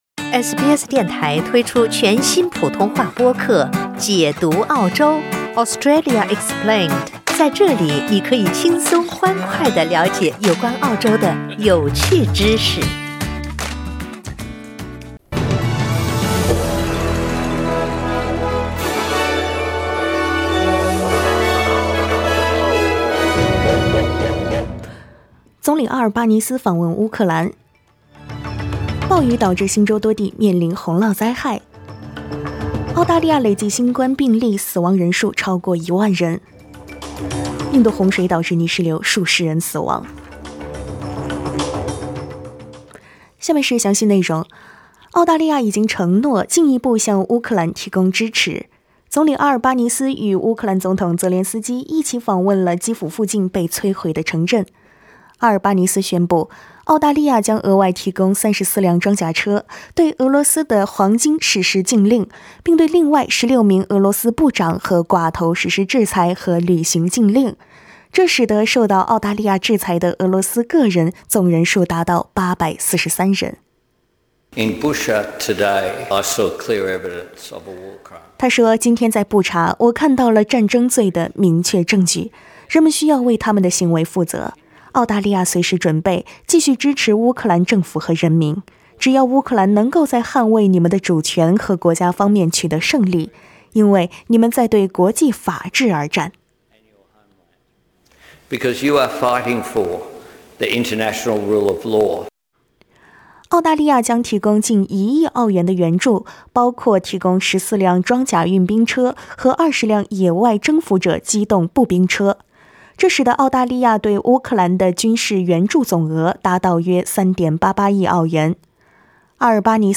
SBS早新闻（7月4日）